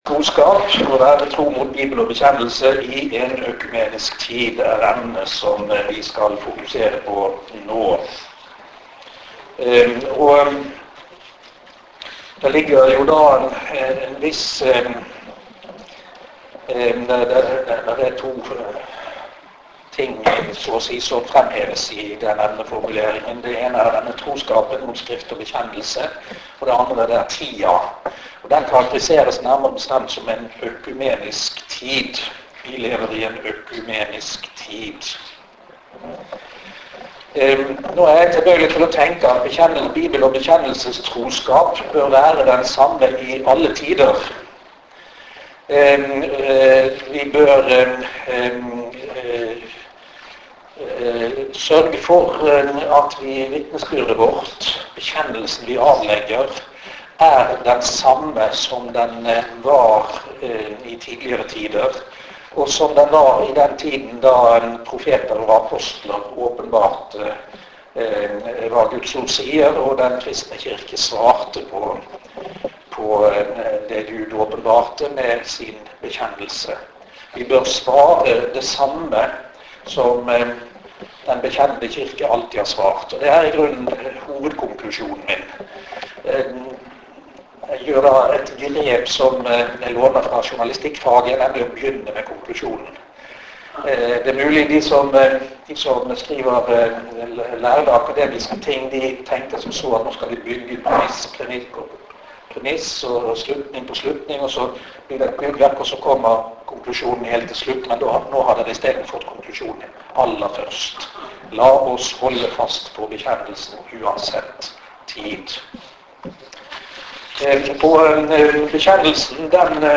Møte i FBB-Østfold